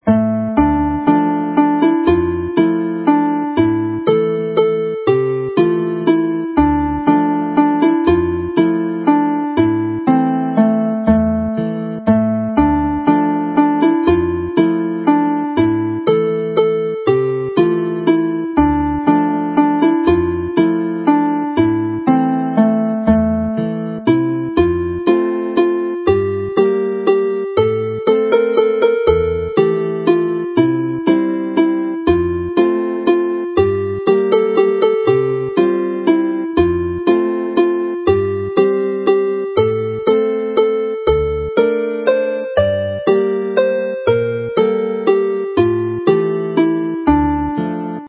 Daw'r Gaeaf (the winter is coming) has the traditional haunting Welsh AABA structure with the first part A in a minor key (Dm) which is restated, then in part Bgoes into the relative major (F) before reverting to the minor of part A to finish.